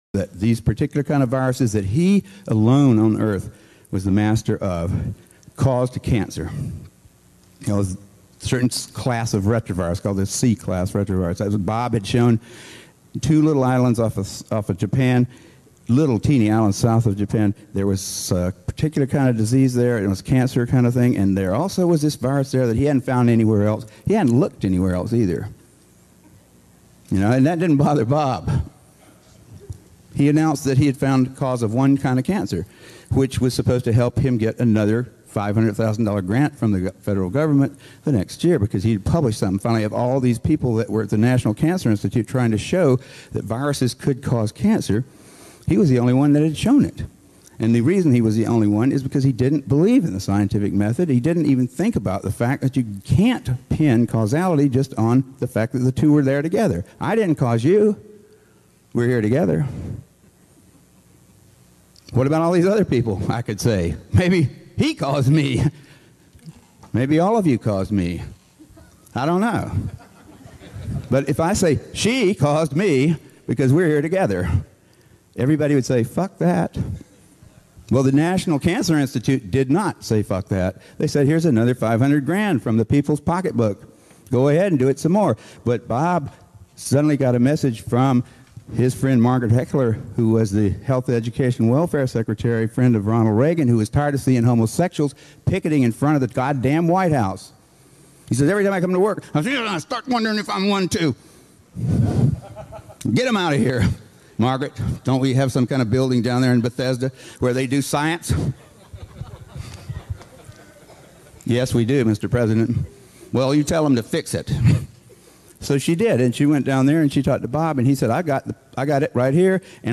Experts Interviews